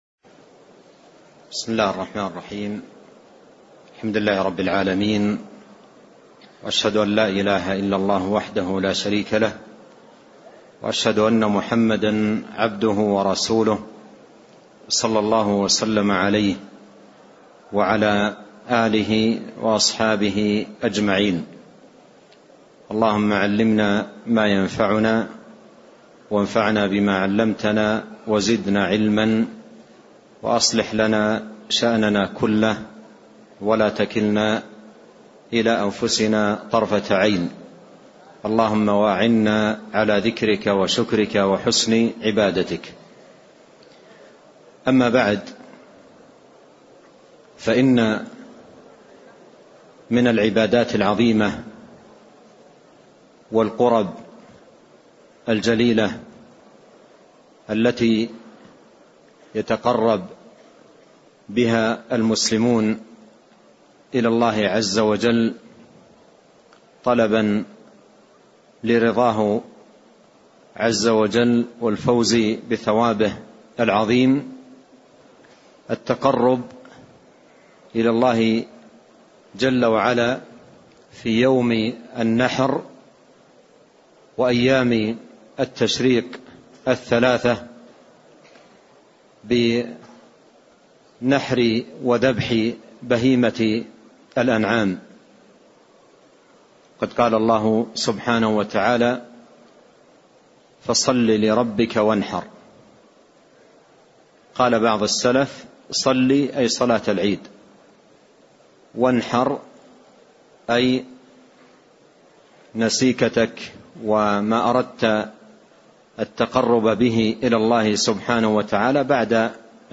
محاضرة - الأضاحي حكم وأحكام